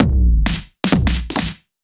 OTG_Kit 1_HeavySwing_130-D.wav